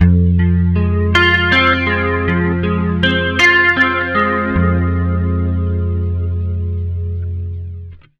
80MINARP F-L.wav